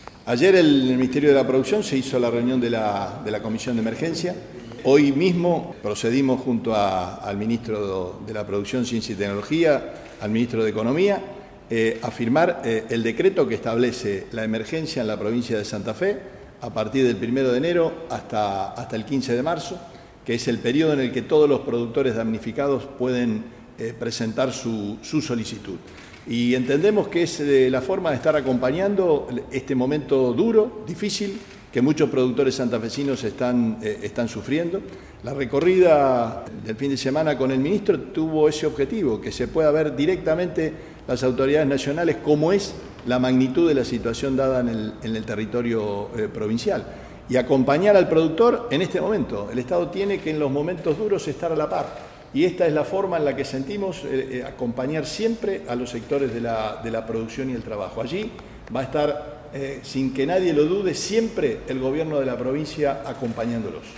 Audio-Perotti_Declaracion-Emergencia-Agropecuaria.mp3